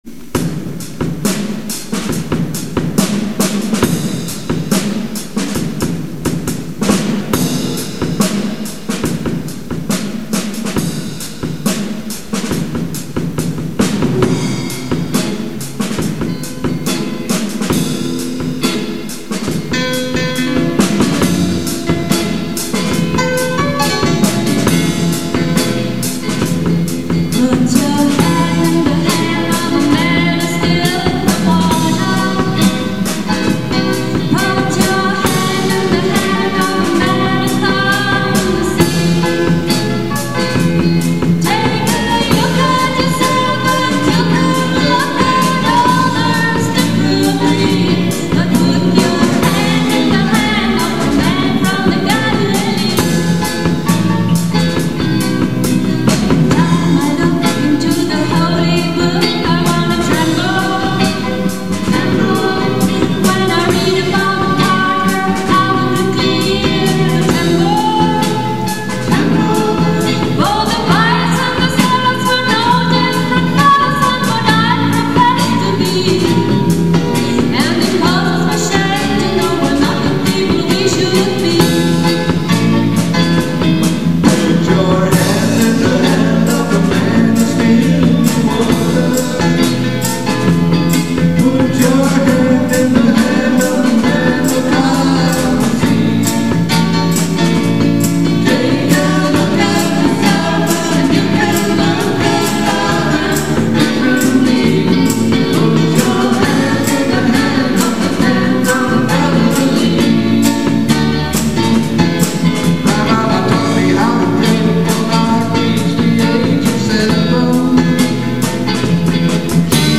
alle Aufnahmen sind "live" in der Kirche mitgeschnitten und deshalb keine CD-Qualität